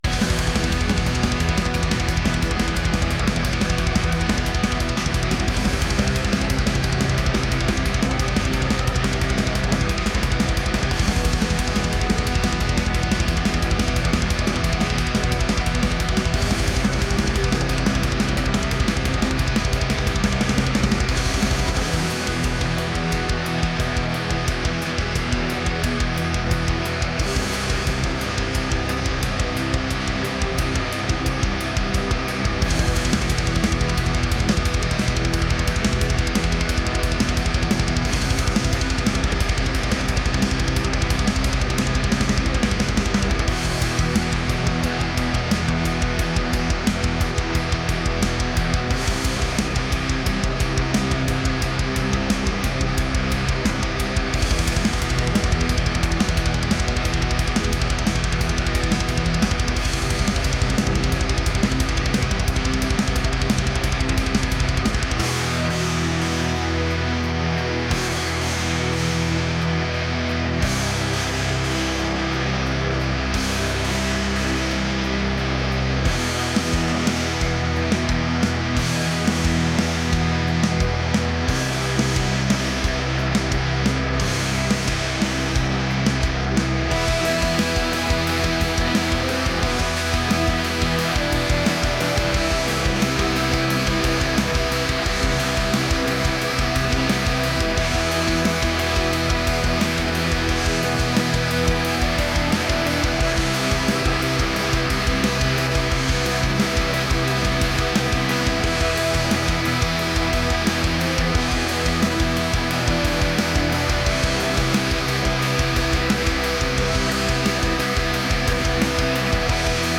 metal | aggressive | atmospheric